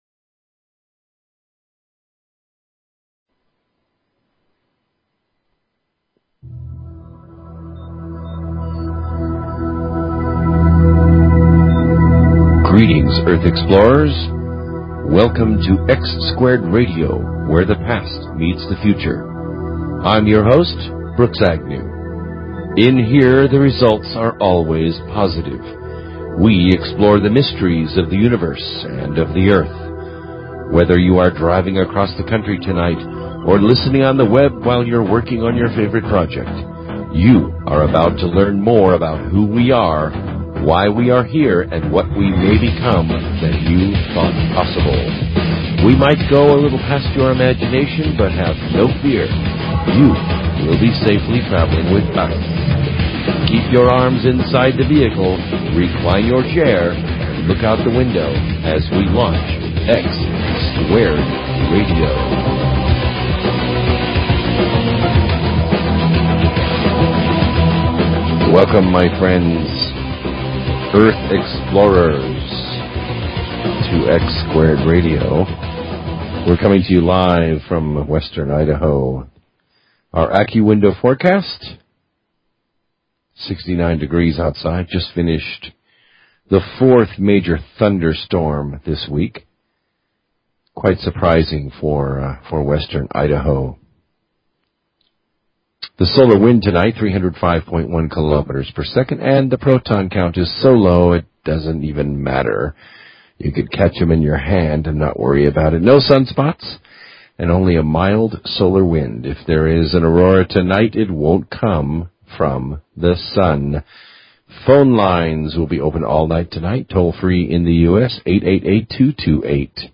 Talk Show Episode, Audio Podcast, X-Squared_Radio and Courtesy of BBS Radio on , show guests , about , categorized as
The calls are fast and amazing int he third hour as the nature of God and Source and Man are discussed in very thought-provoking dialog.